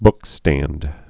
(bkstănd)